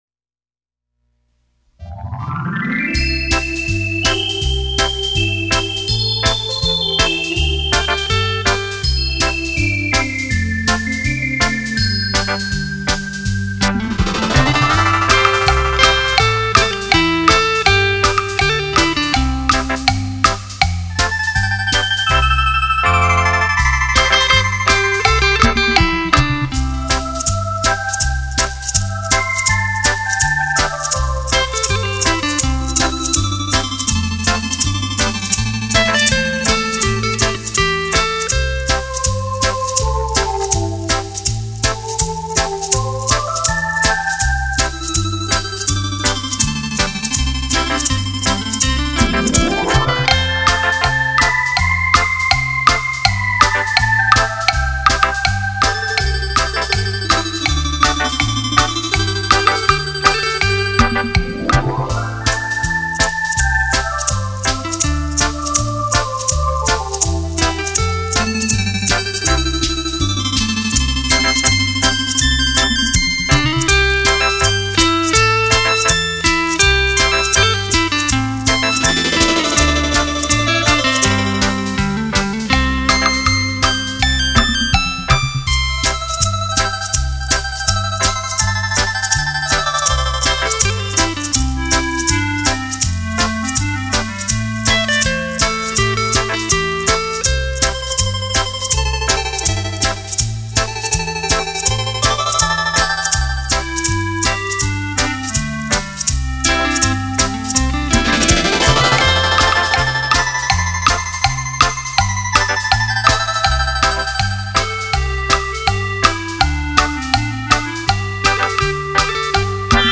专辑类型：电子琴音乐